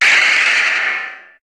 Cri de Méga-Branette dans Pokémon HOME.
Cri_0354_Méga_HOME.ogg